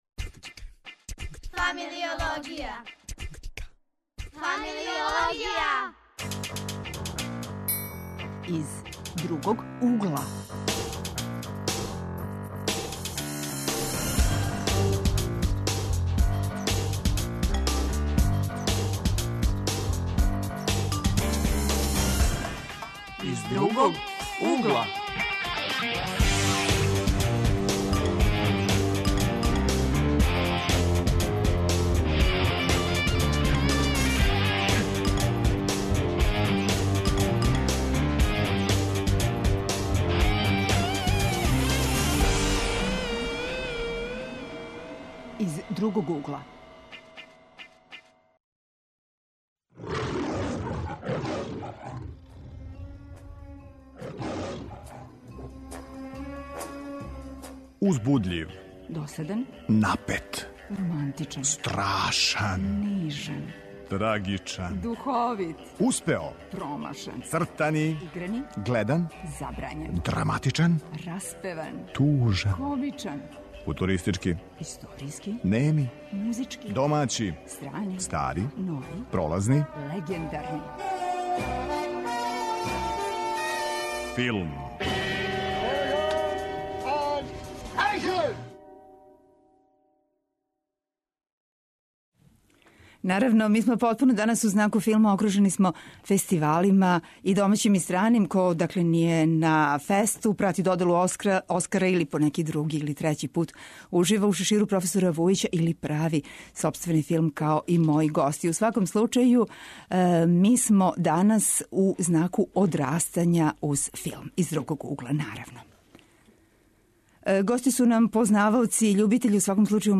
Одрастање уз филм - тема је Другог угла. Гости су нам студенти режије који ће се осврнути на филмове уз које су одрастали, на Фест, али и на филмове које они сами данас праве.